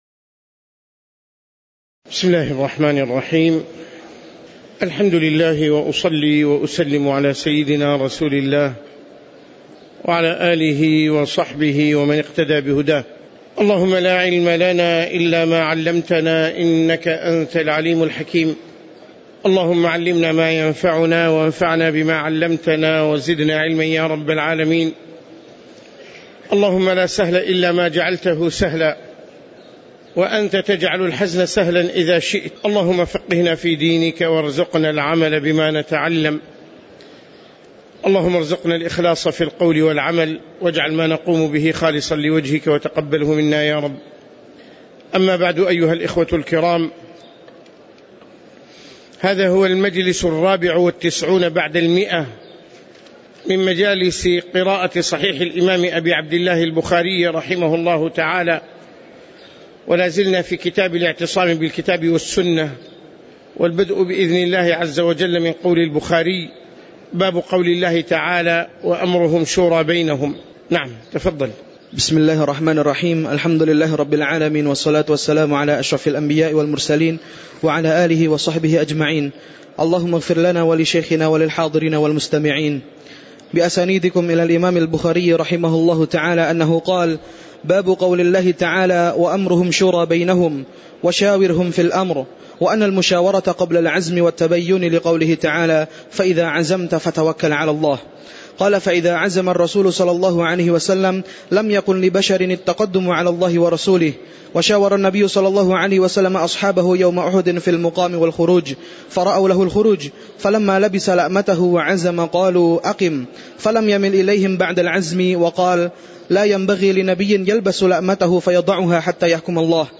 تاريخ النشر ٨ جمادى الأولى ١٤٣٩ هـ المكان: المسجد النبوي الشيخ